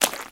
STEPS Swamp, Walk 11.wav